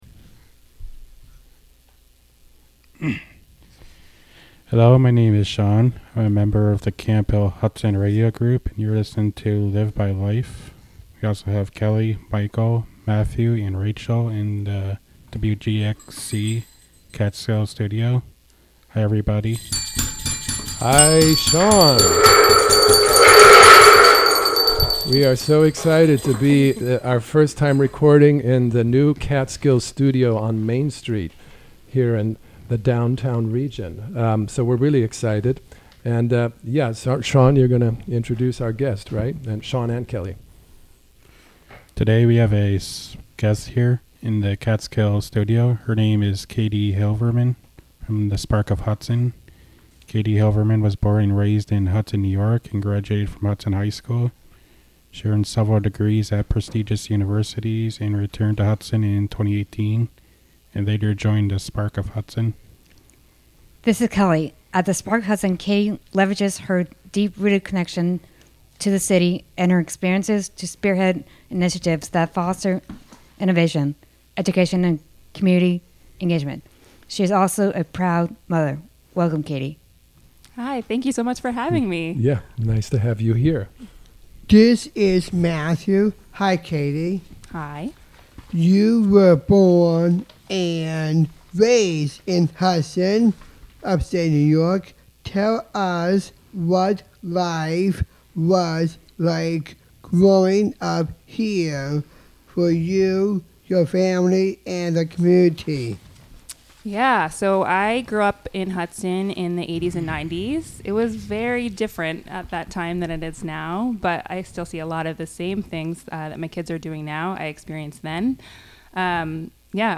(Audio) May 09, 2024 shows Live By Life Produced by the Camphill Hudson Radio Group. Interview by the Camphill Media Group.